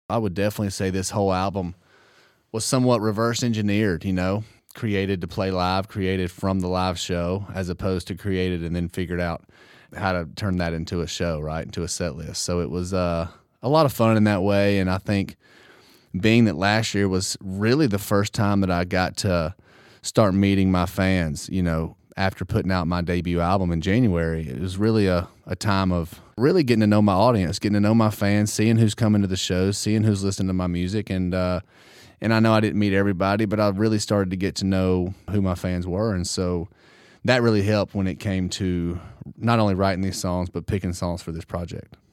Tyler Hubbard says Strong was reverse engineered from the way his debut solo album was written and recorded.